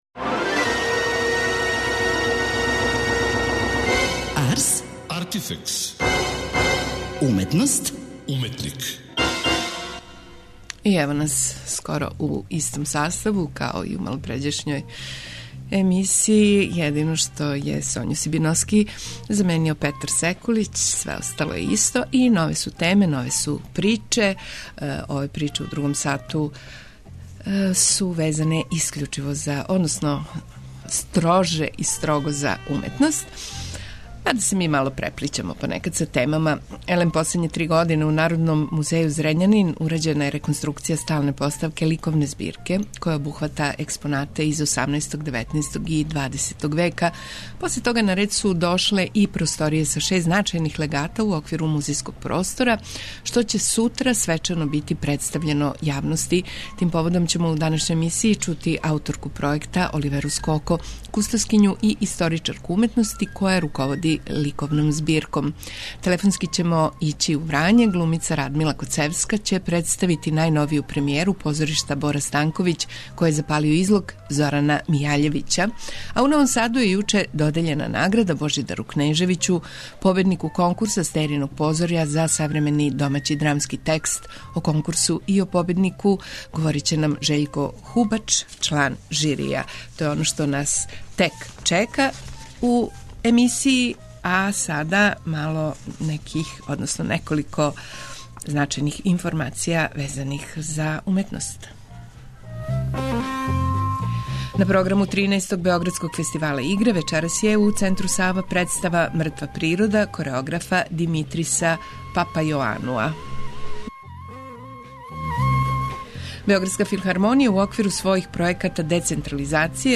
Телефонски идемо у Врање;